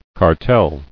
[car·tel]